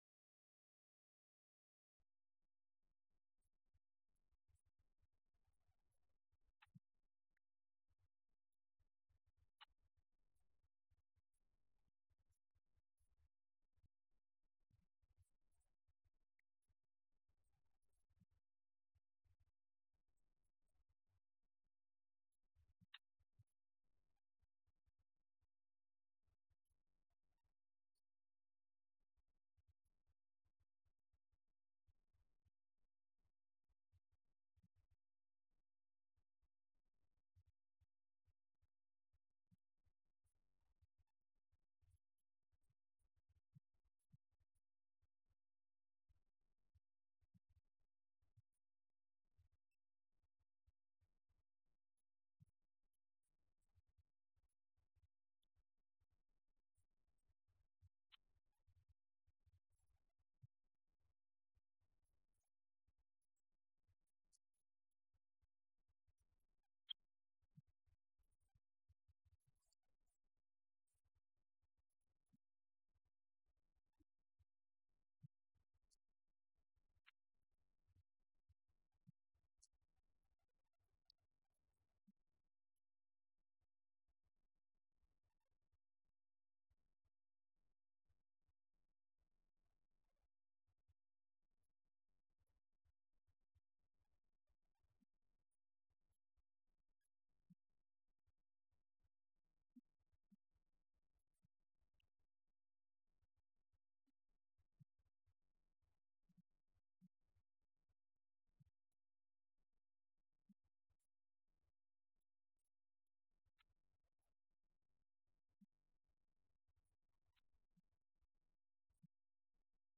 Event: 17th Annual Schertz Lectures Theme/Title: Studies in Job